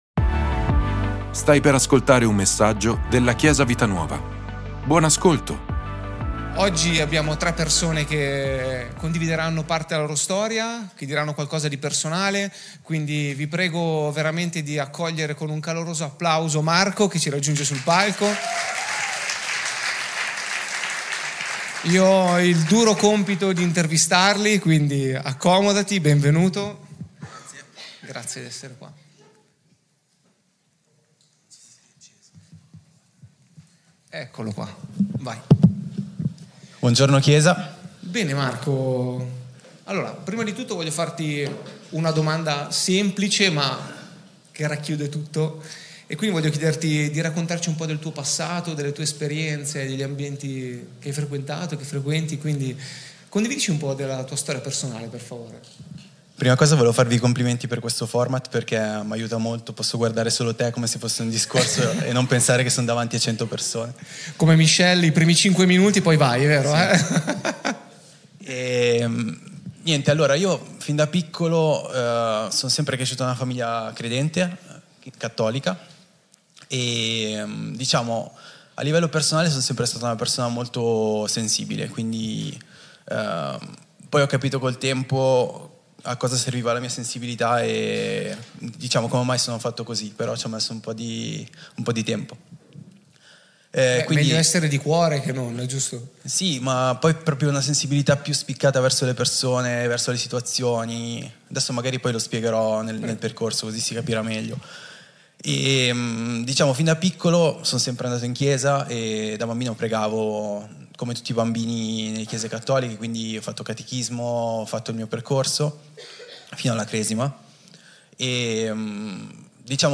Ascolta la predicazione "Chi è Gesù per te? " di Chiesa Vita Nuova.